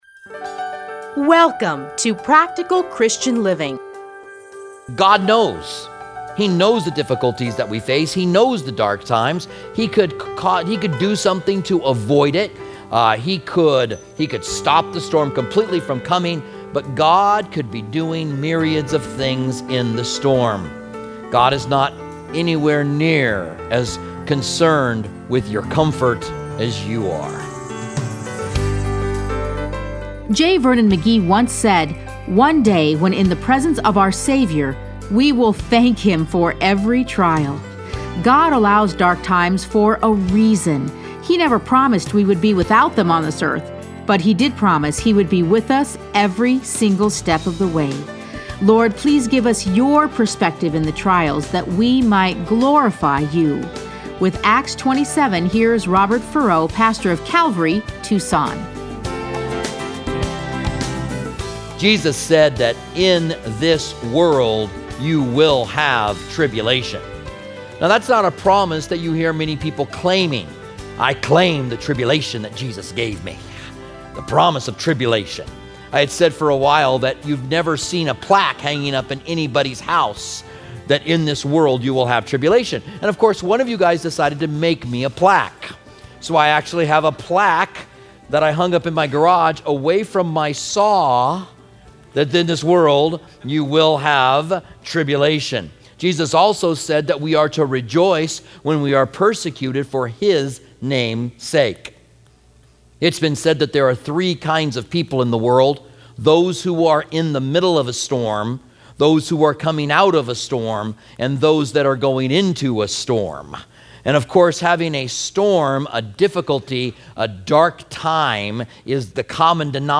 Listen to a teaching from Acts 27.